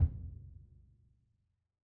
BDrumNewhit_v3_rr2_Sum.wav